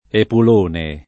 epul1ne] s. m. — con e- minusc. anche con riferim. o allus. al «mangione egoista» della parabola evangelica: un ricco epulone alzatosi allora da tavola [un r&kko epul1ne alZ#toSi all1ra da tt#vola] (Manzoni); talvolta con E- maiusc. se inteso come n. pr.: i minuzzoli che egli, Epulone e Trimalcione dei lacchezzi e dei bocconcini ghiotti, spazzava via di quando in quando [